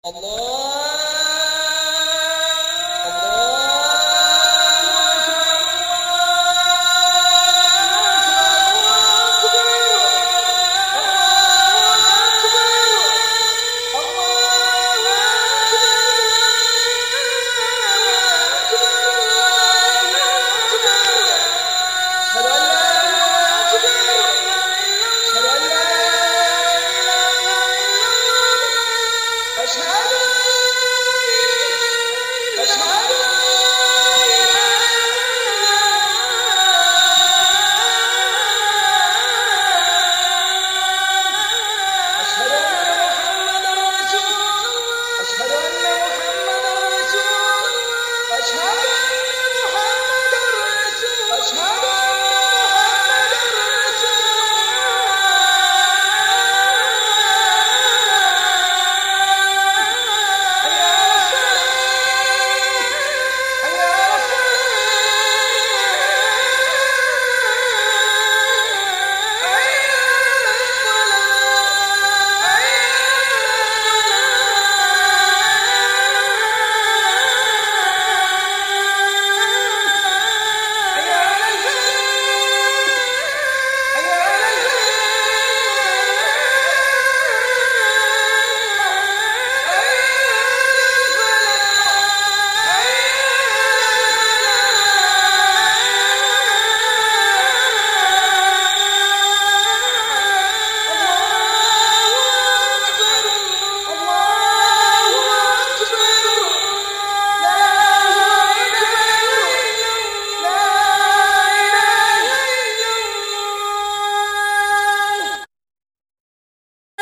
أذان مؤثر خاص بالرقية - المكتبة الإسلامية
Azaan.mp3